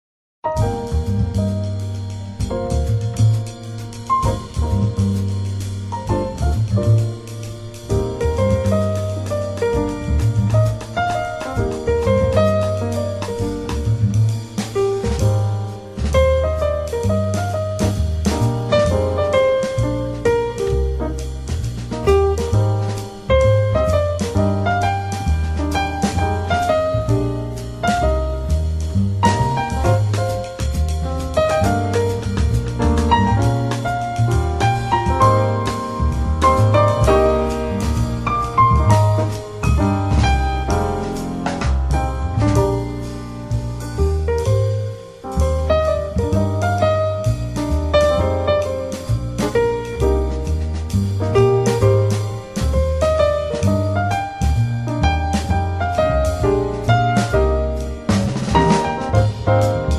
爵士钢琴三重奏：木星-欢乐使者